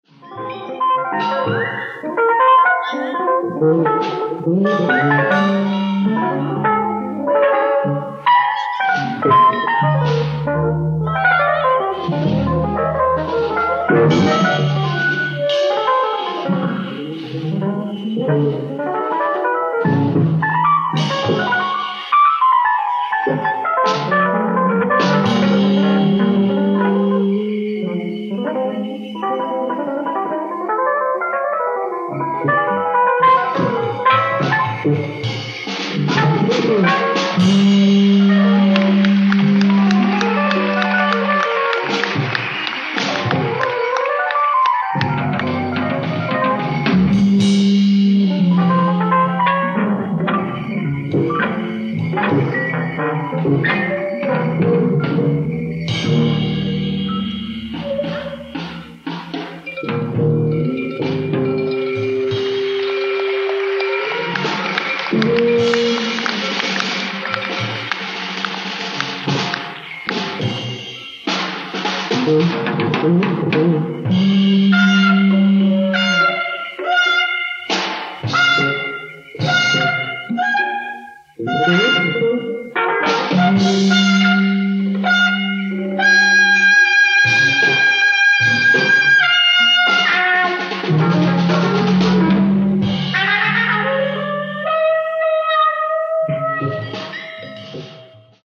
BAND RECORDING ON THE STAGE